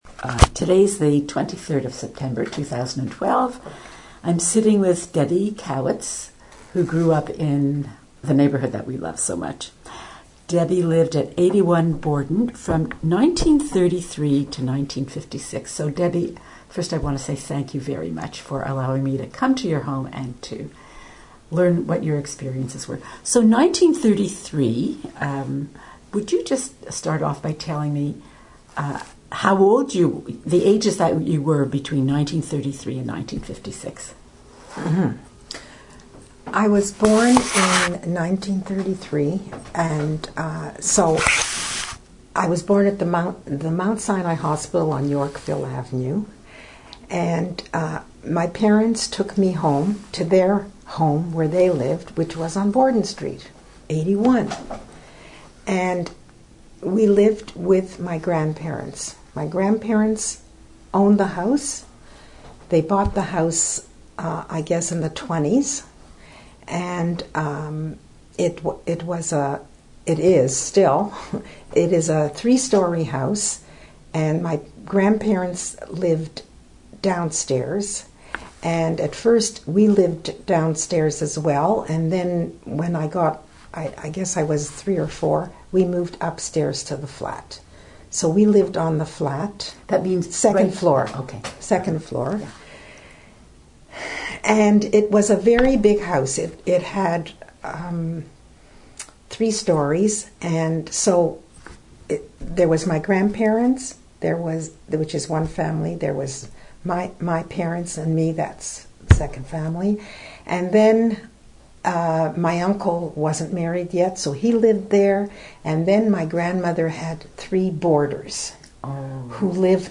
Interview TRANSCRIPT